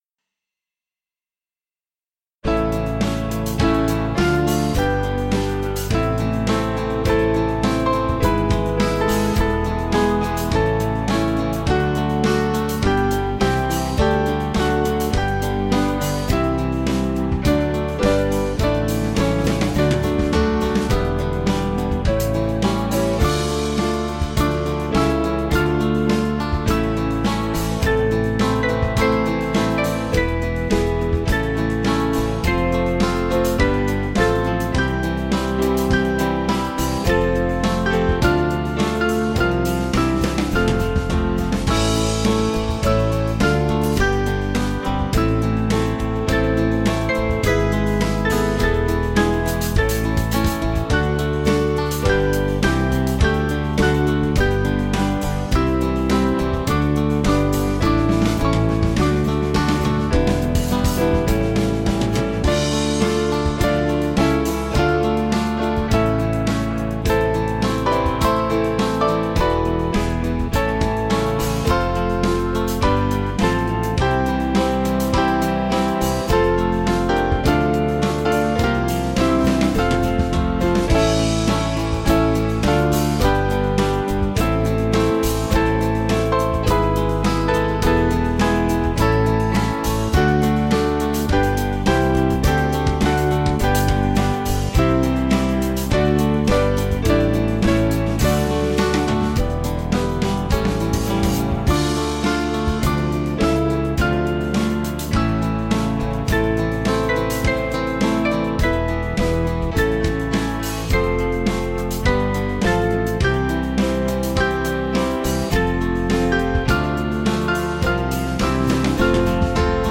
Small Band
(CM)   4/Dm 488.7kb